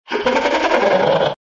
Download Gorilla Tag sound effect for free.